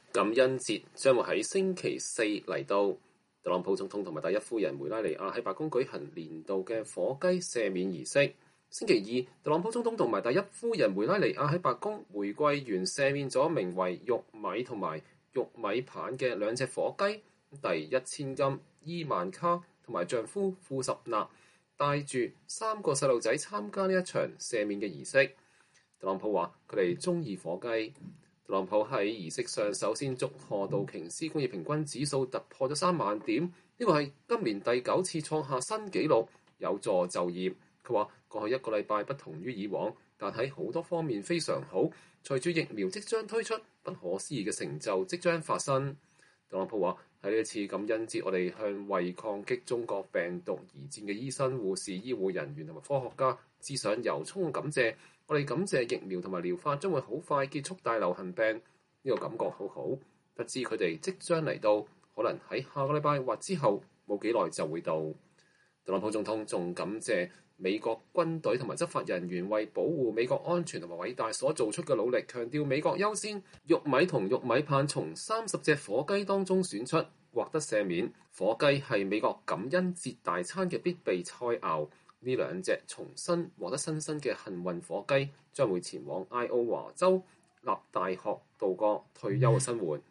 感恩節將在星期四到來，特朗普總統和第一夫人梅拉尼婭在白宮舉行年度火雞赦免儀式。